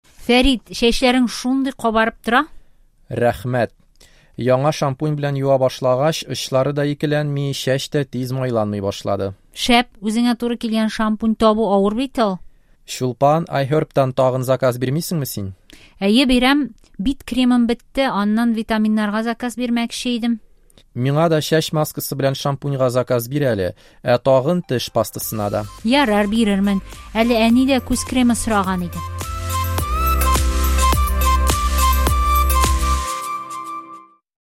ДИАЛОГ: “Заказ бирәсеңме?”
Урок включает в себя набор необходимой лексики и фраз, жизненные диалоги с аудио озвучкой, интересные фразы, тест для самопроверки.